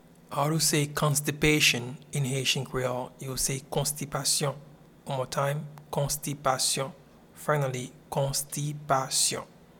Pronunciation and Transcript:
Constipation-in-Haitian-Creole-Konstipasyon.mp3